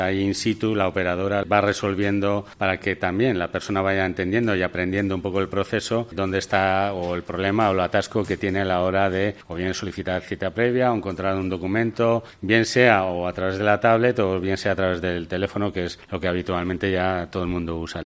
Kilian Cruz-Dunne, concejal de Participación Ciudadana